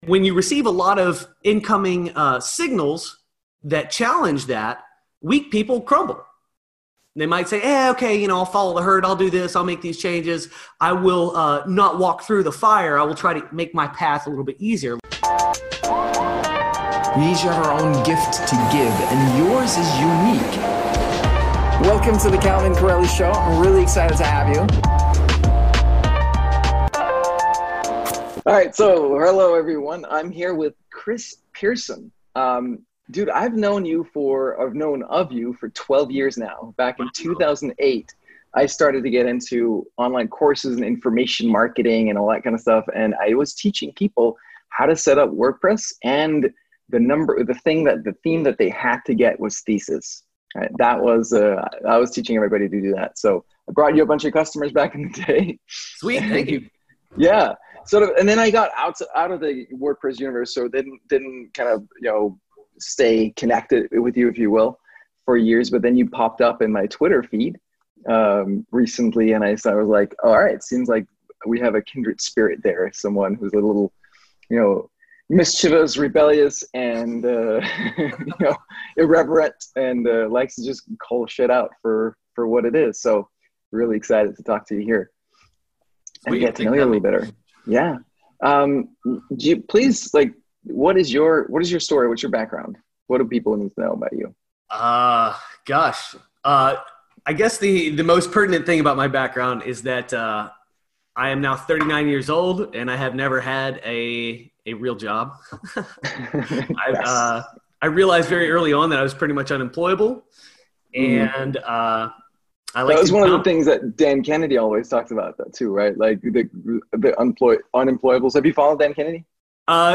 Software, Politics, and Cancel Culture An Interview